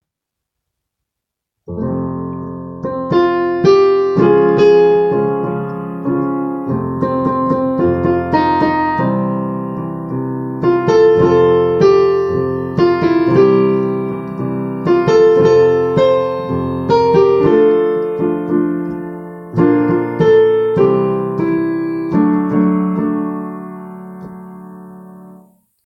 The beginning (and ending) of the song (I’ll call you Buster … You saved me from the alien.”) is played here: